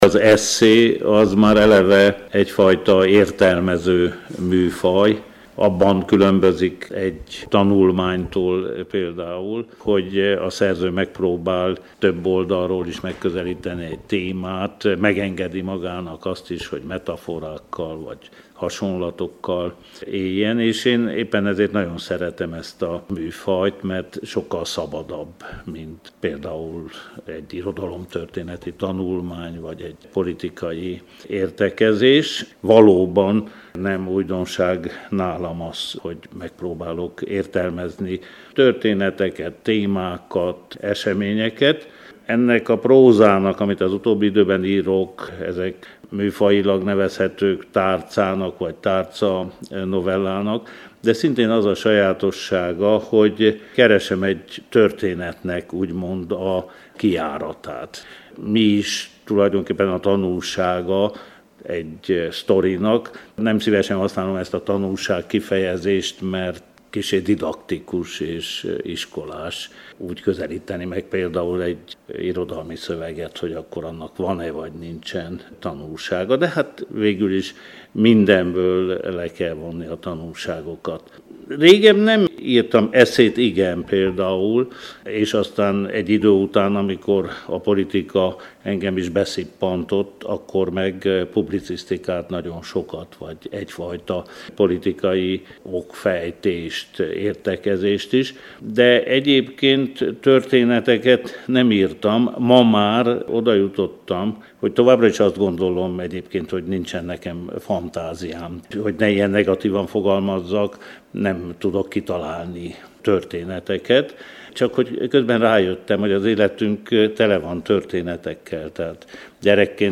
Beszélgetés Markó Bélával prózai írásairól